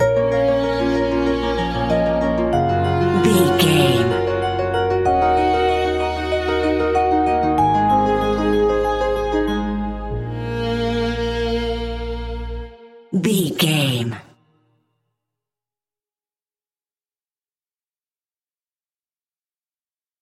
Aeolian/Minor
B♭
dreamy
ethereal
peaceful
melancholy
hopeful
piano
violin
cello
percussion
electronic
synths
synth drums